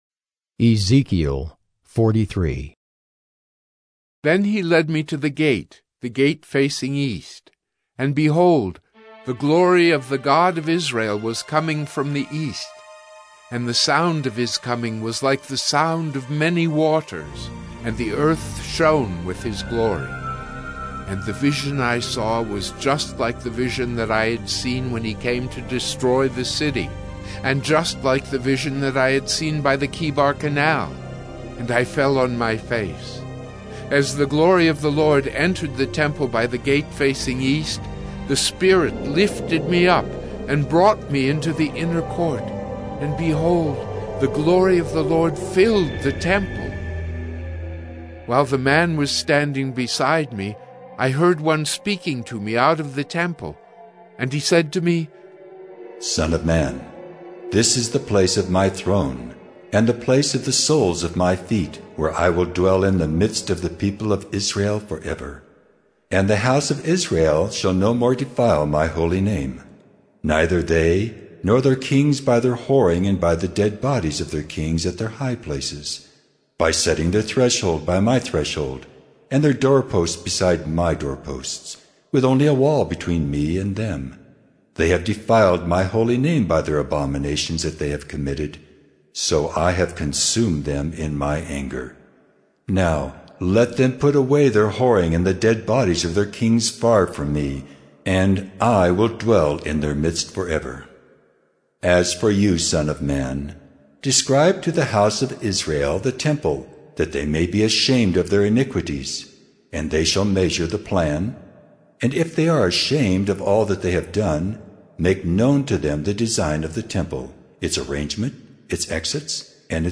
“Listening to God” Bible Reading & Devotion: Oct. 18, 2021 – Ezekiel 043